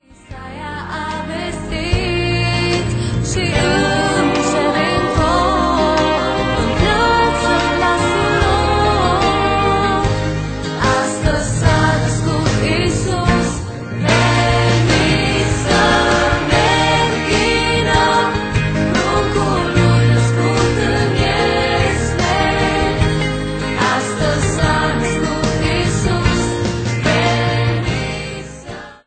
intr-un stil propriu si revigorant